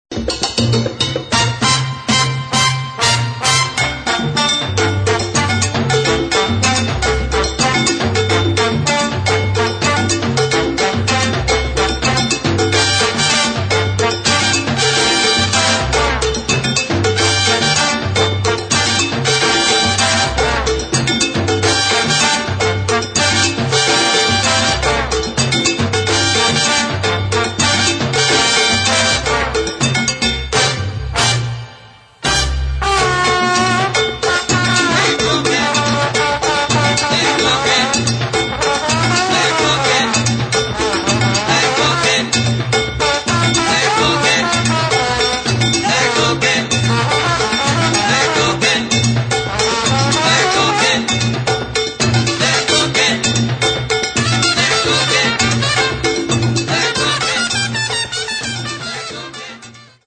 [ FUNK / LATIN ]